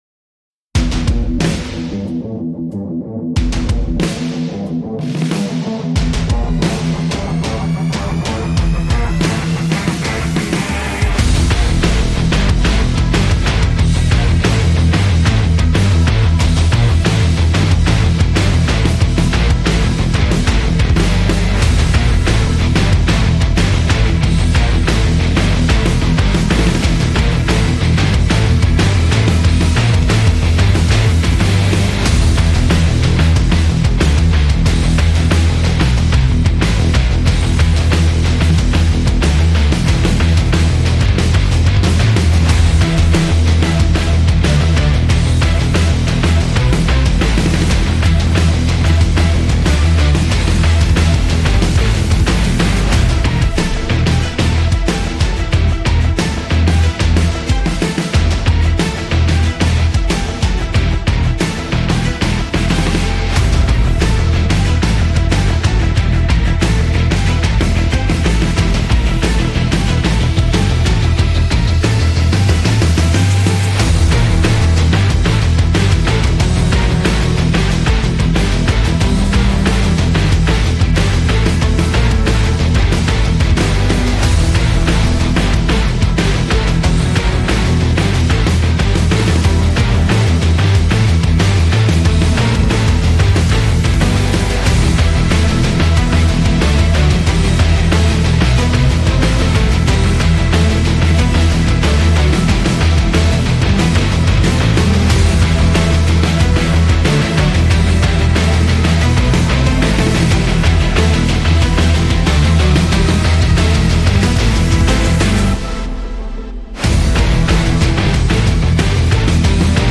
۵. سینماتیک (Cinematic)